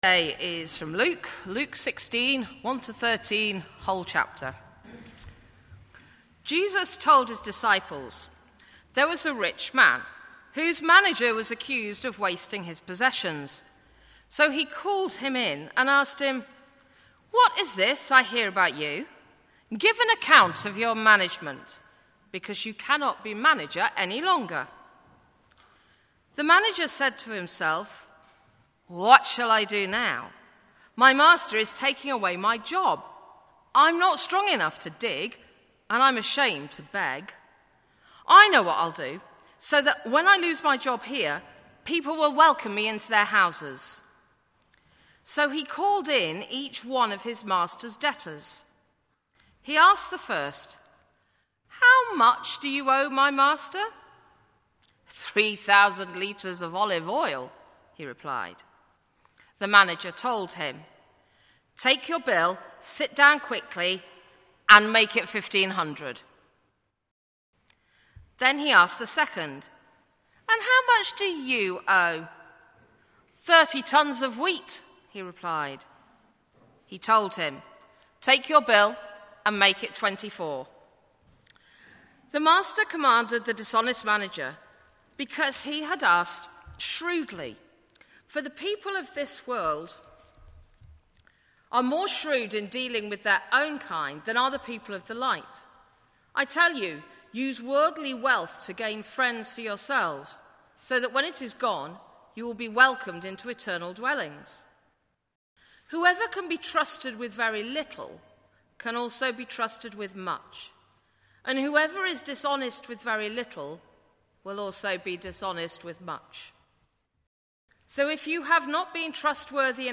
Talk
10:30 Morning Worship, St John's service